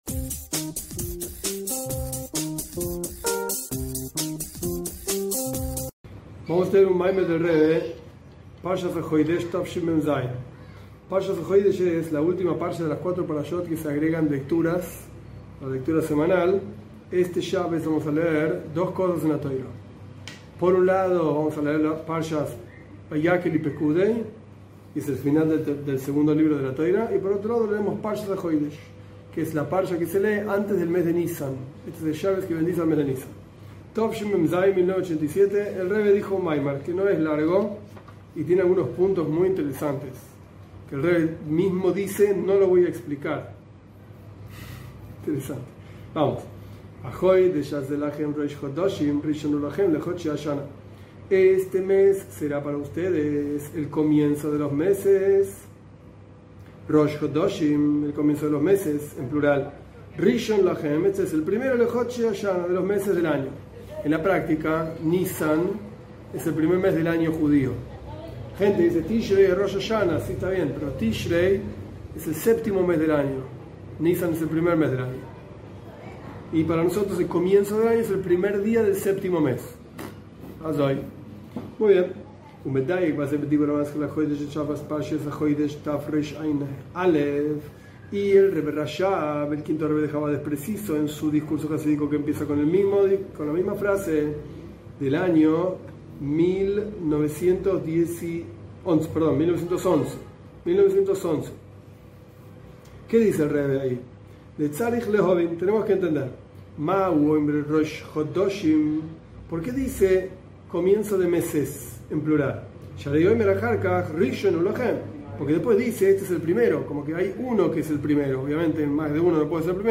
Este es un discurso jasídico del Rebe, Rabí Menajem Mendel Schneerson, del año 1987. En este discurso se explica la diferencia entre el comienzo del mes de Tishrei y el comienzo del mes de Nisan.